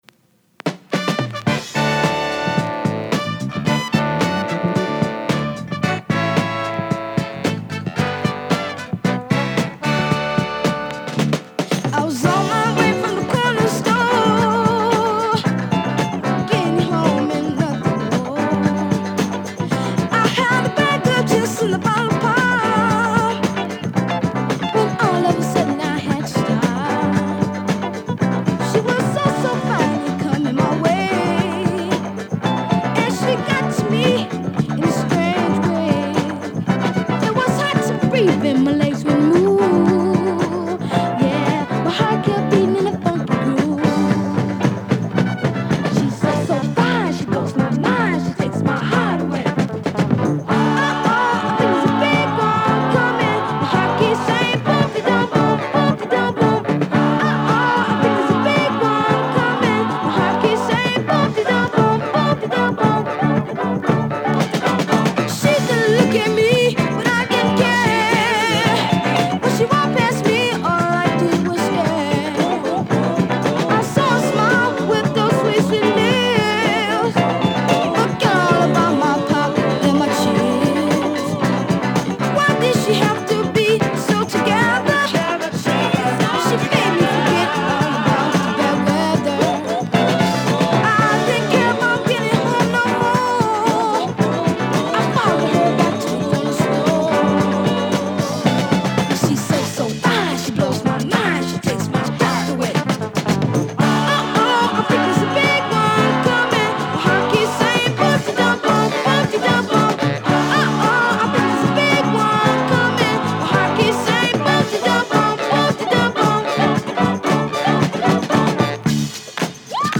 ジャクソン5タイプのグルーヴィーでポップなレア・キッズ・ソウル！